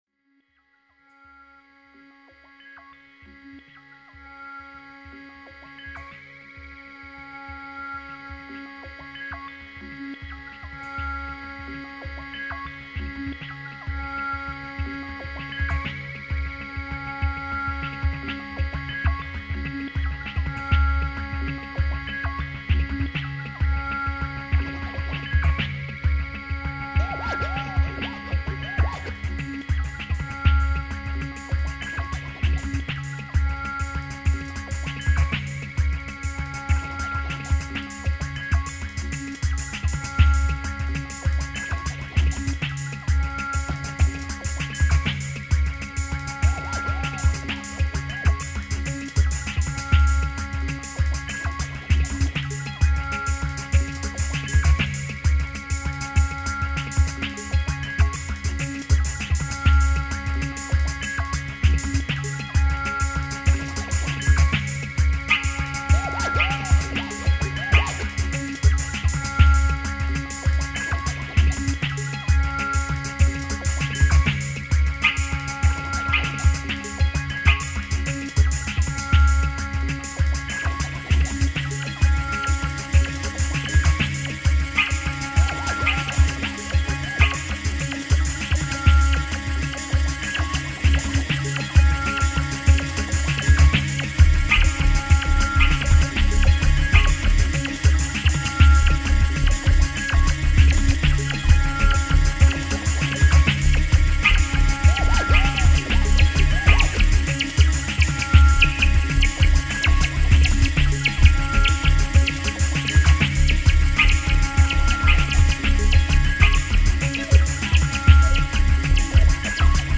(mpc2000, mc-505, mc-303, mackie 1402 & kaoss-pad)
@ sondermann studio Cologne, Germany 1998 & 1999